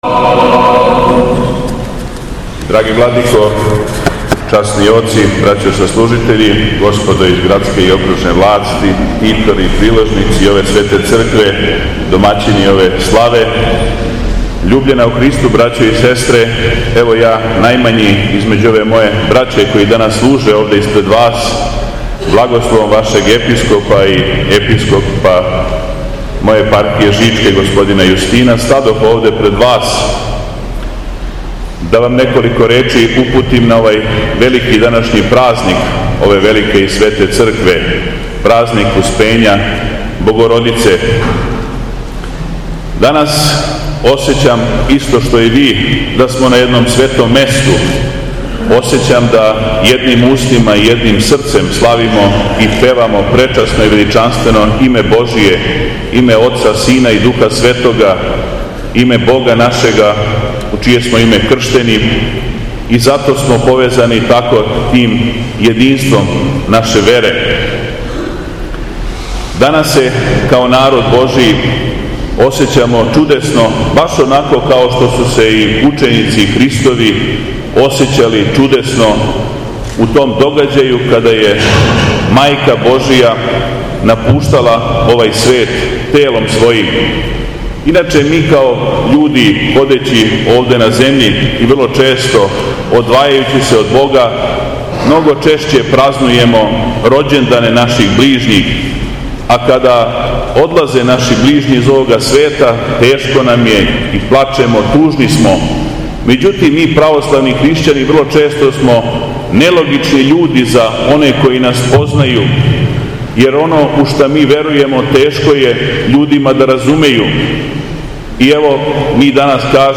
Саборни храм у Крагујевцу је 28. августа прославио своју храмовну славу Успења Пресвете Богородице.
Беседа Његовог протојереја